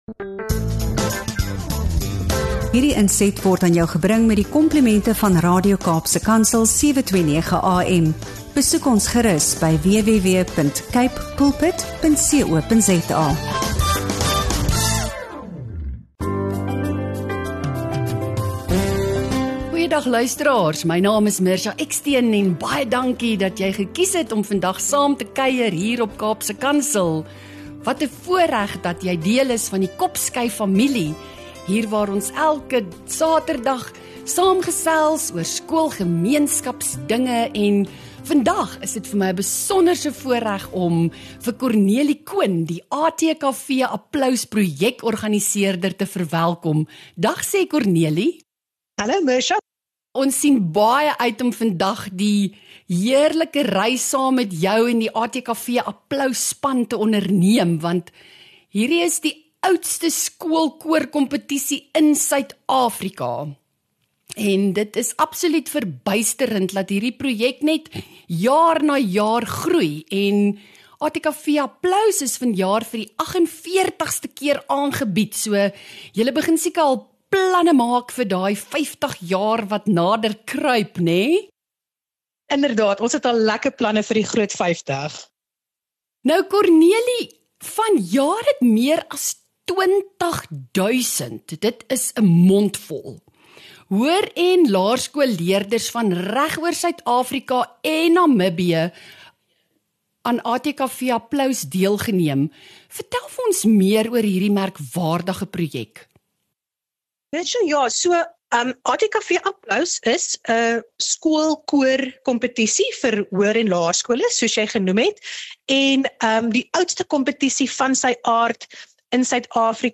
Sluit aan vir 'n inspirerende gesprek oor die impak van die ATKV Applaus Projek op die gemeenskap en die lewens van die deelnemers.